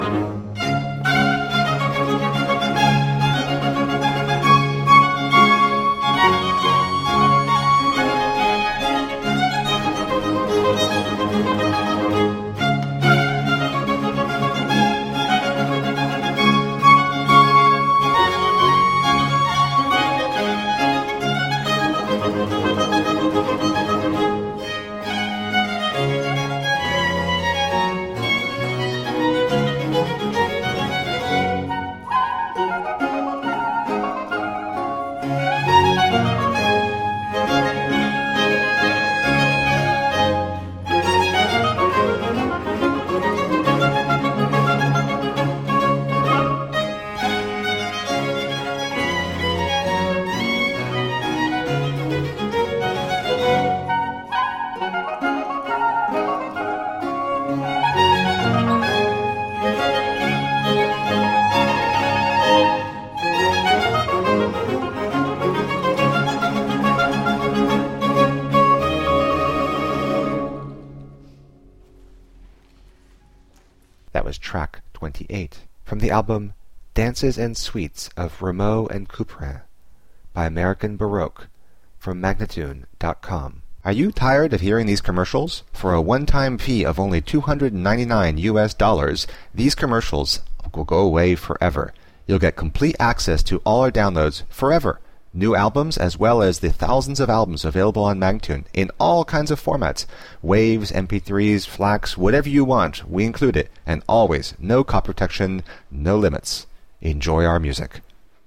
Spectacular baroque and classical chamber music.
Flute, Harpsichord, Oboe, Violin